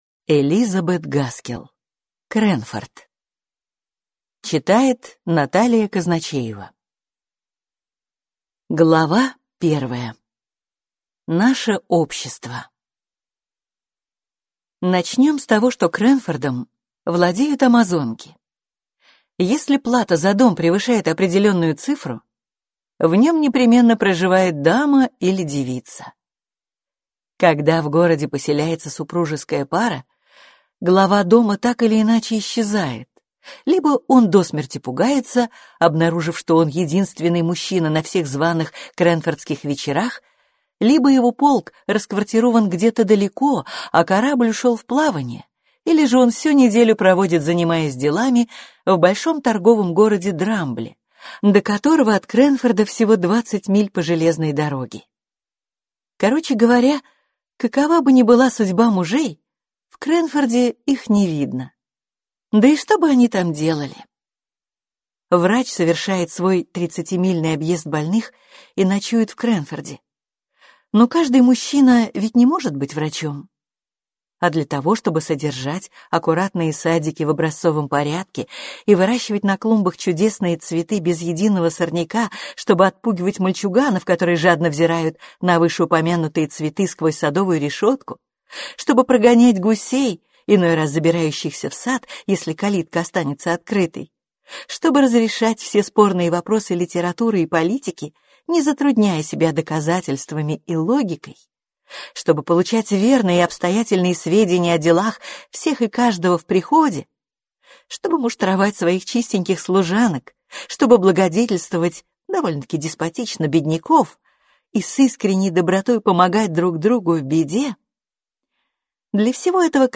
Аудиокнига Крэнфорд | Библиотека аудиокниг